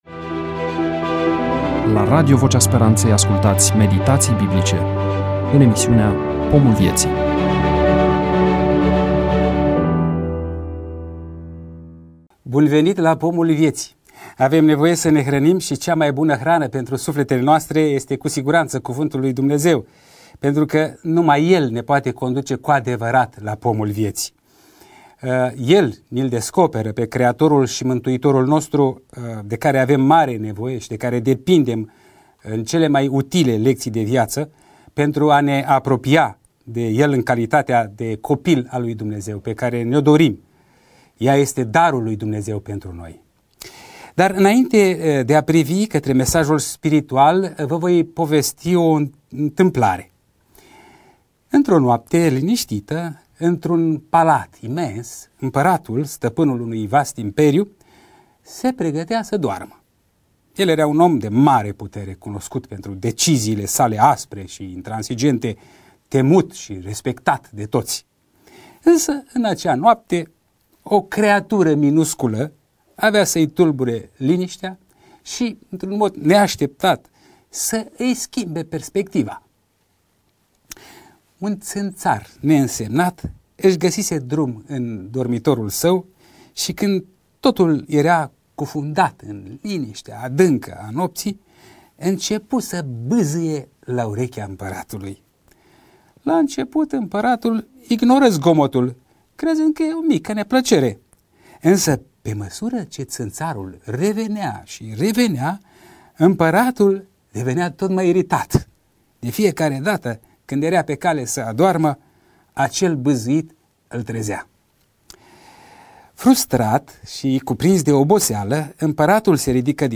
EMISIUNEA: Predică DATA INREGISTRARII: 20.11.2024 VIZUALIZARI: 10